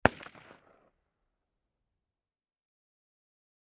Rugar Mini 14 Machine Gun Single Shot From Distant Point of View, X3